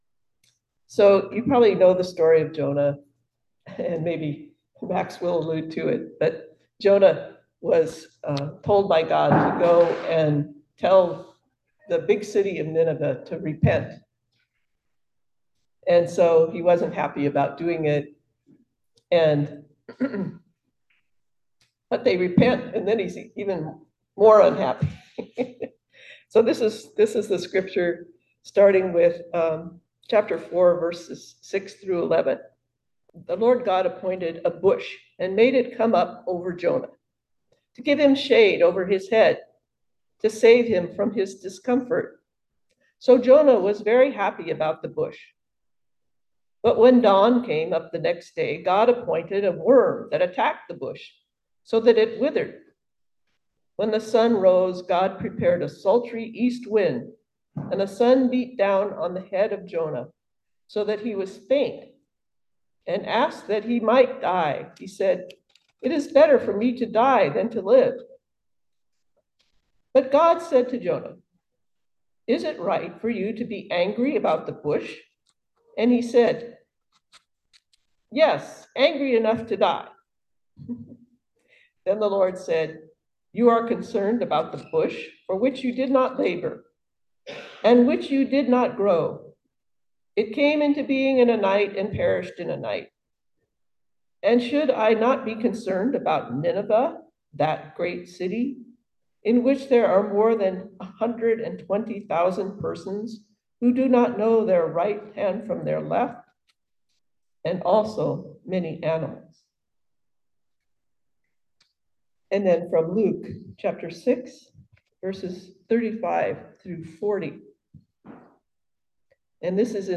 Message for December 29, 2024